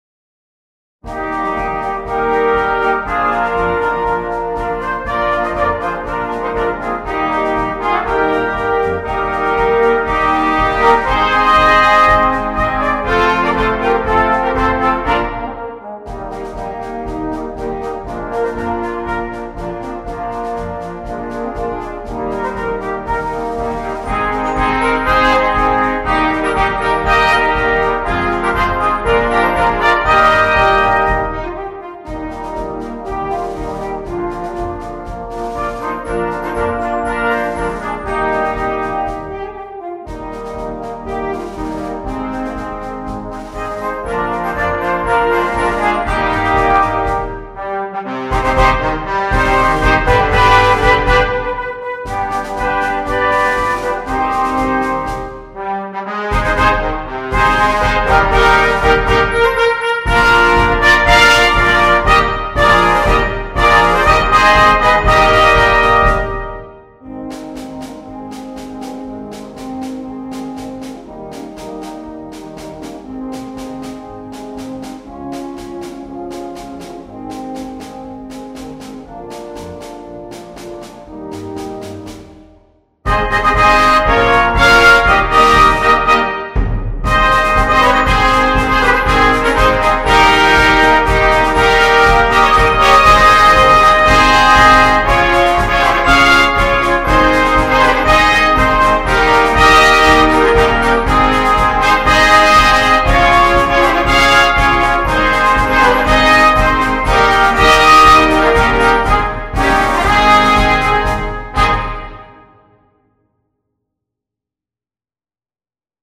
2. Brass Band
Full Band
without solo instrument
Entertainment, Polka